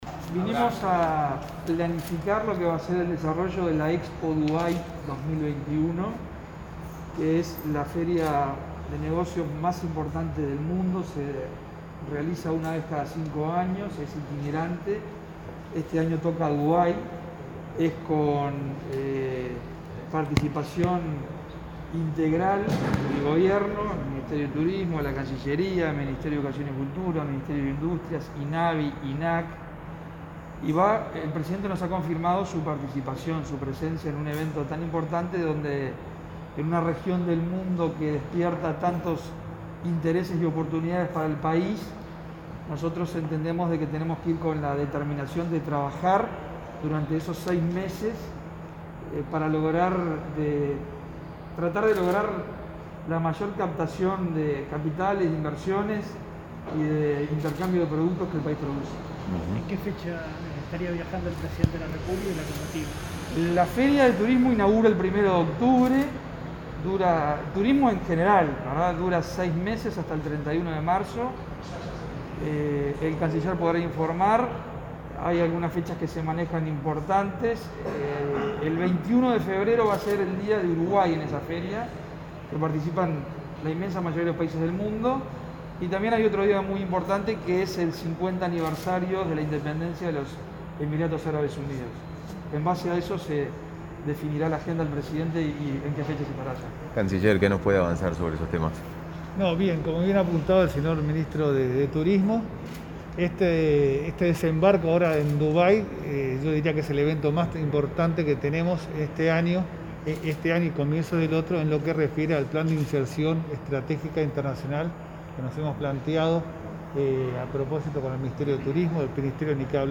Declaraciones del canciller Francisco Bustillo y del ministro de Turismo, Germán Cardoso
Este jueves 15, los ministros Bustillo y Cardoso se expresaron en una conferencia de prensa para informar los temas abordados en su reunión con el